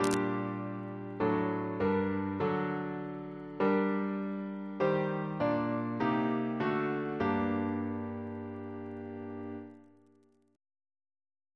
CCP: Chant sampler
Single chant in G minor Composer: W. Sterndale Bennett (1816-1875) Reference psalters: PP/SNCB: 114